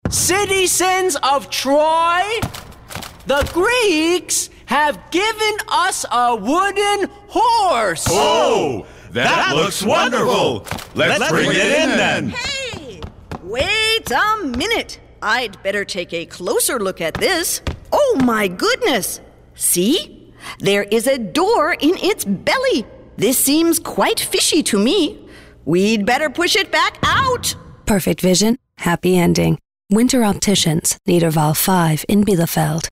Sprecherin amerikanisch englisch für Werbung, Sport, Kultur und Musiksendungen, Schulungsfilme, Dokumentationen, PC-Spiele, Zeichentrickfilme
middle west
Sprechprobe: eLearning (Muttersprache):